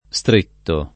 Str&nSi]; part. pass. stretto [